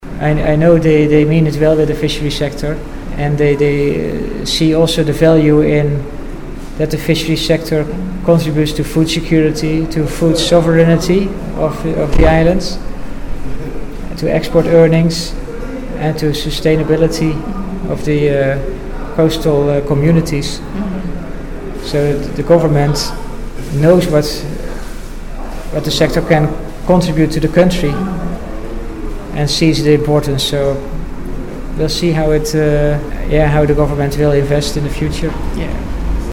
He was speaking on the sidelines of a recent workshop aimed at improving the emergency response framework for disasters affecting coastal and fishing communities.